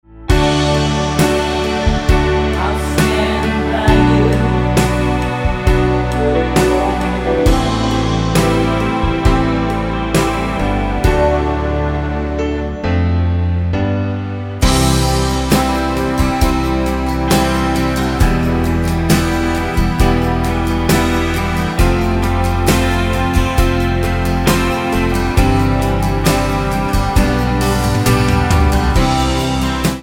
Tonart:D mit Chor
Die besten Playbacks Instrumentals und Karaoke Versionen .